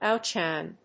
音節òut・shíne 発音記号・読み方
/ˈaʊˌtʃaɪn(米国英語)/